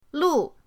lu4.mp3